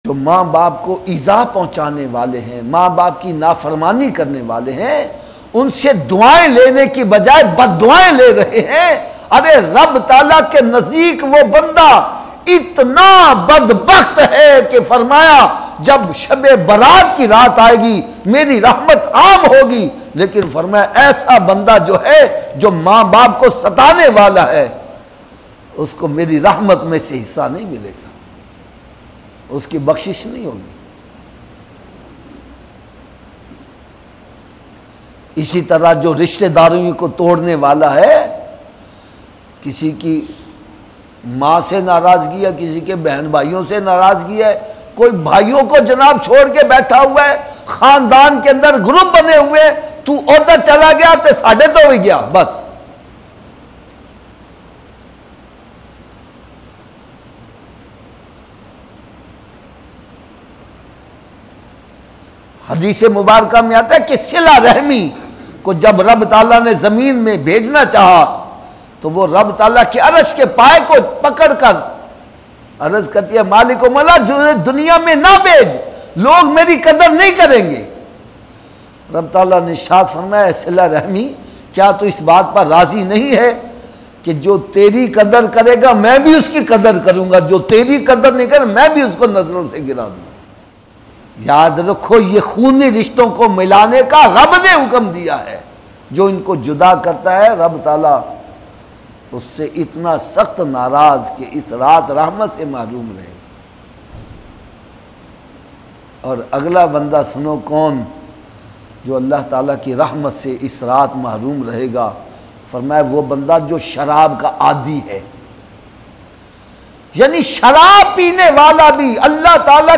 Shab e Barat ki Raat pe Mehrom Log Lattest Bayan MP3